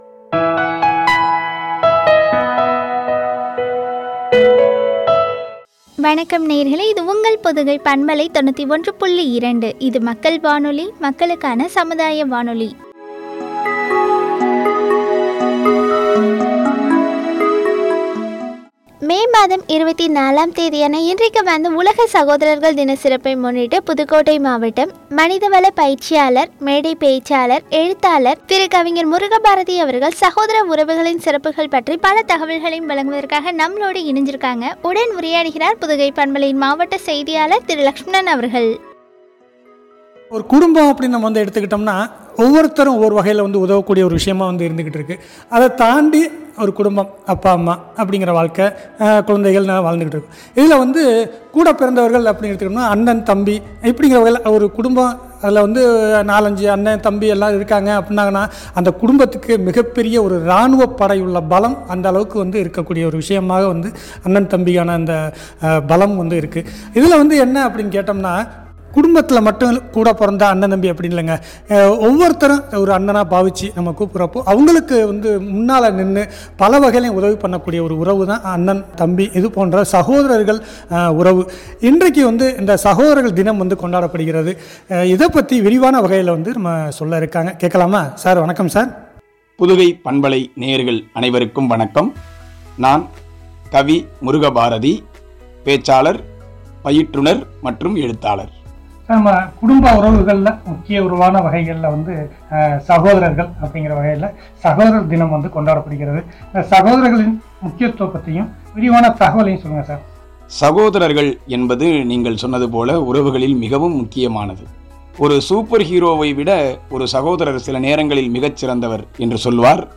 சகோதர உறவுகளின் சிறப்புகள் பற்றிய உரையாடல்.